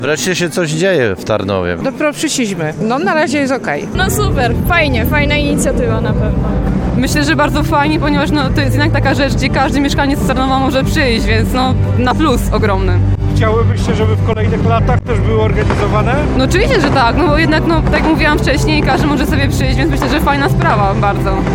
Mieszkańcy, w rozmowie z reporterem RDN Małopolska cieszyli się z powrotu miejskiej imprezy sylwestrowej: -Wreszcie się coś dzieje w Tarnowie!